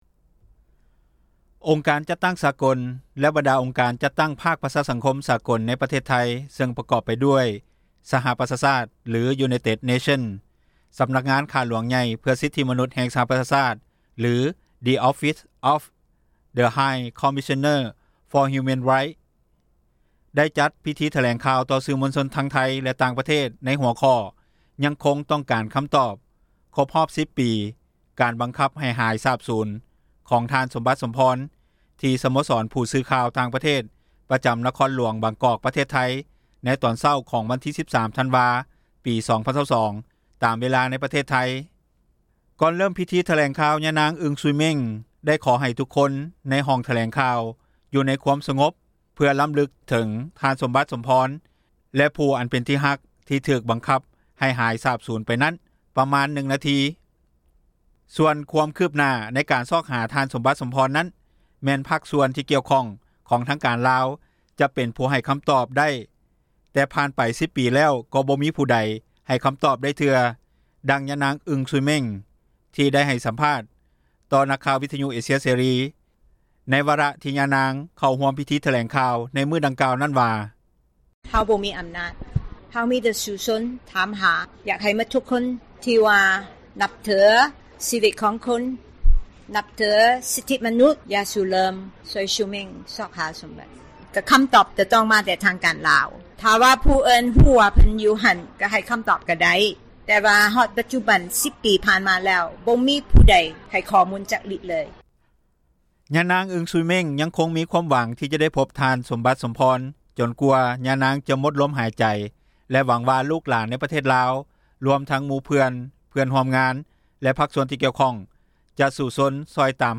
10 ຜ່ານໄປ ນາໆຊາຕ ຍັງທວງຖາມຫາ ທ່ານ ສົມບັດ ສົມພອນ ພິທີຖແລງຂ່າວ ຕໍ່ສື່ມວນຊົນທັງໄທຍ ແລະຕ່າງປະເທດ ໃນຫົວຂໍ້ “ຍັງຄົງ ຕ້ອງການ ຄຳຕອບ” ຄົບຮອບ 10 ປີ ການບັງຄັບ ໃຫ້ຫາຍສາບສູນ ຂອງ ສົມບັດ ສົມພອນ ທີ່ສະໂມສອນ ຜູ້ສື່ຂ່າວຕ່າງປະເທດ ປະຈຳ ນະຄອນຫລວງ ບາງກອກ ປະເທດໄທຍ ໃນຕອນເຊົ້າຂອງວັນທີ 13 ທັນວາ 2022.